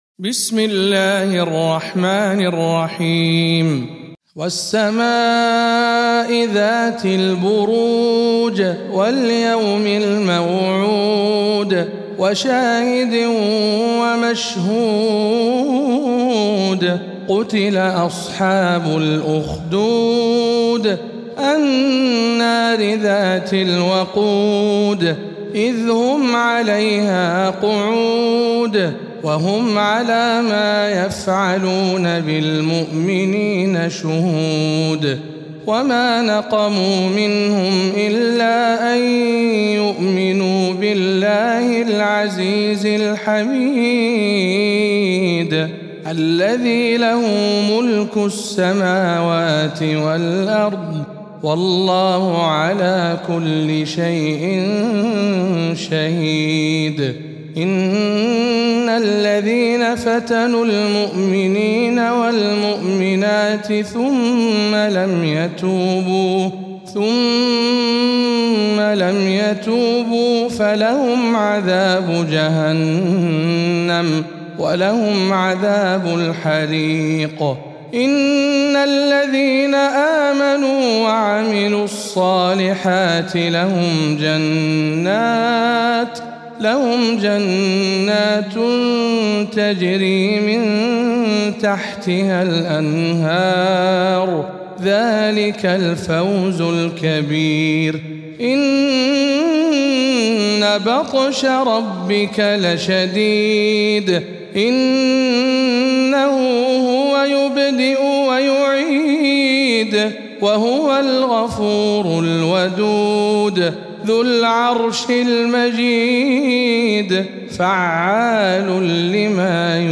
سورة البروج -رواية رويس عن يعقوب